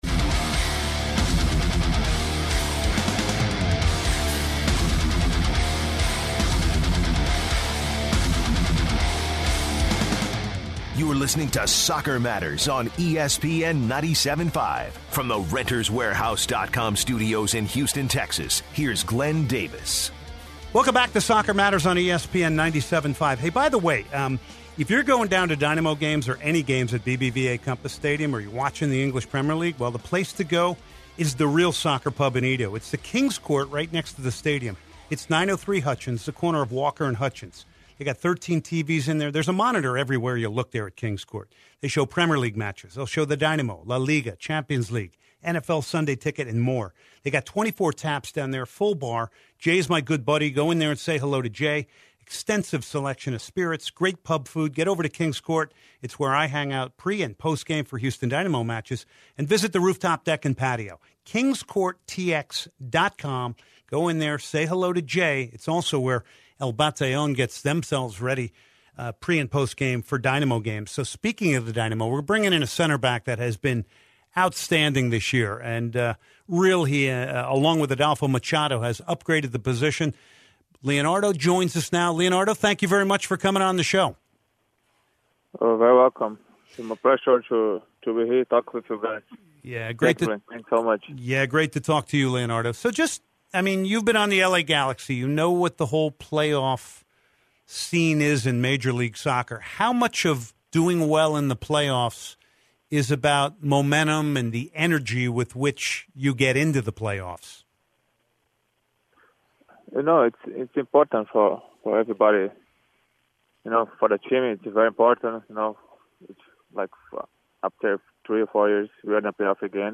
Next he talks with FS1 soccer analyst Stuart Holden and then finishes with Dynamo midfielder Ricardo Clark.